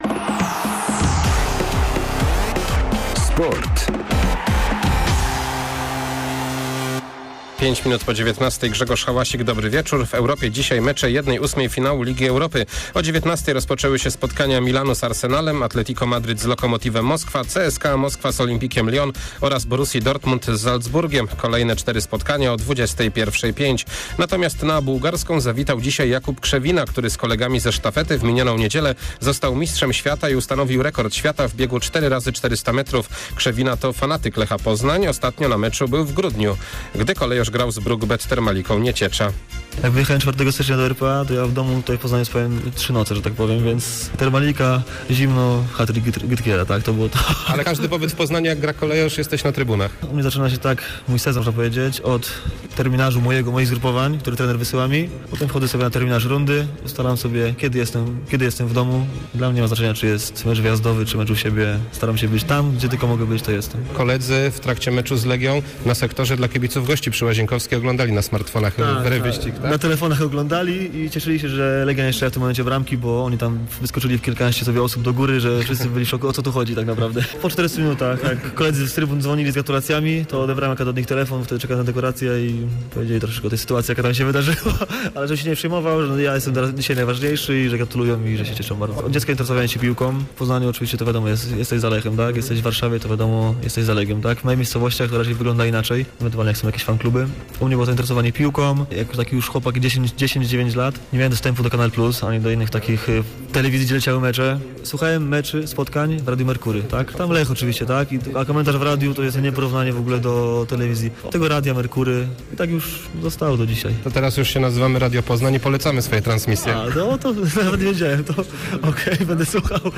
08.03 serwis sportowy godz. 19:05